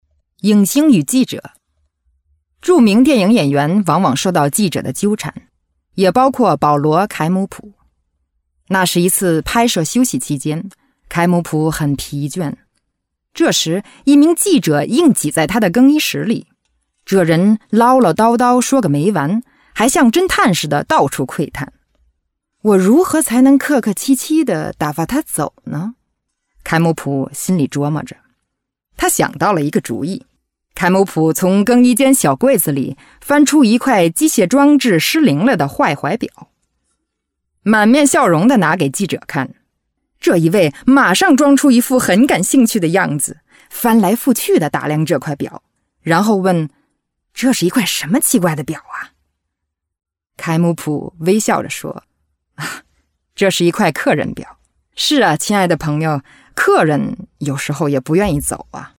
Native Speaker Chinesisch Sprecherin, Werbesprecherin
Demo Mix